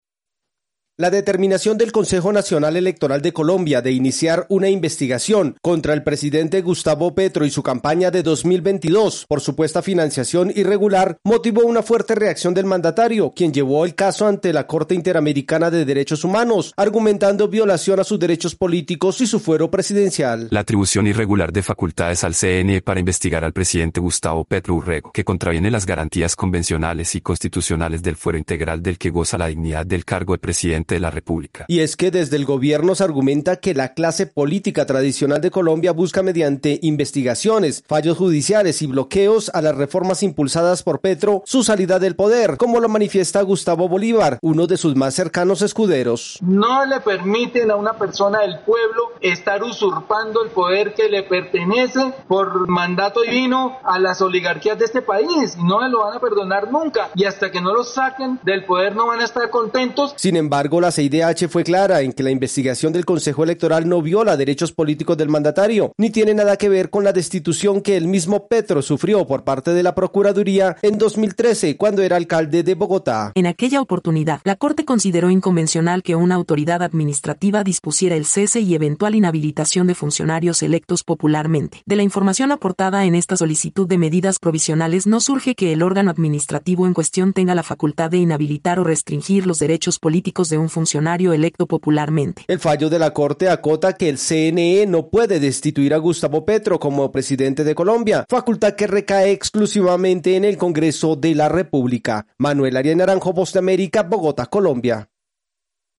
Mientras el presidente de Colombia insiste en un golpe de Estado contra su gobierno, la Comisión Interamericana de Derechos Humanos sentenció que no hay violación a sus derechos políticos y le negó medidas cautelares. Desde Colombia informa el corresponsal de la Voz de América